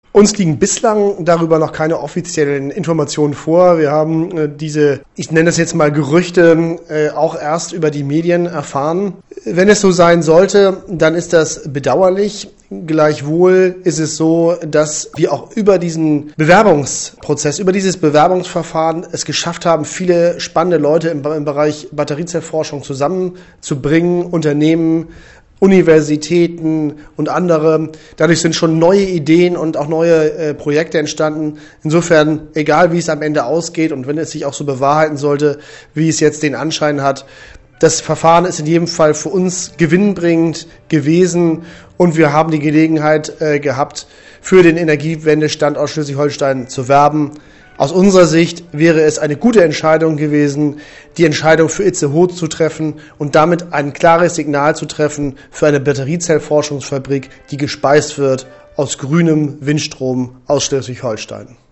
IMG_1520Weiter erklärte Rohlfs